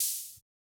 Index of /musicradar/retro-drum-machine-samples/Drums Hits/Tape Path A
RDM_TapeA_MT40-OpHat02.wav